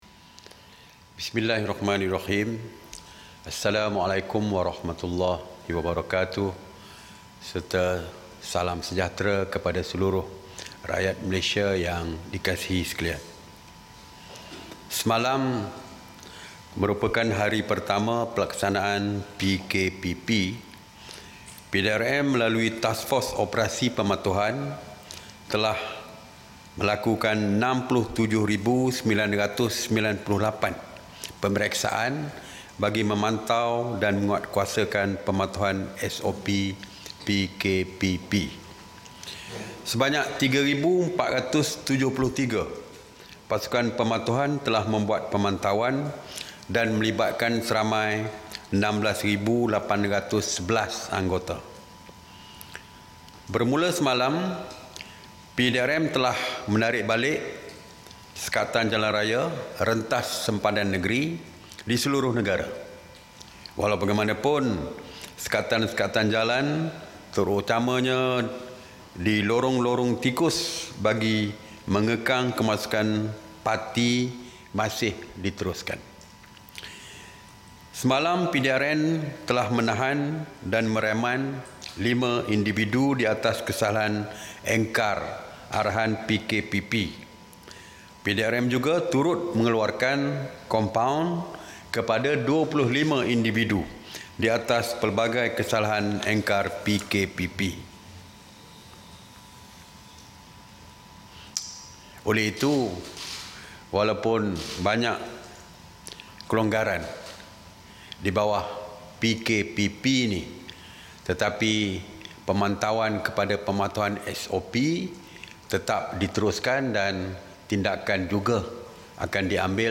Ikuti sidang media oleh Menteri Kanan Keselamatan, Datuk Seri Ismail Sabri Yaakob berkaitan Perintah Kawalan Pergerakan Pemulihan, PKPP.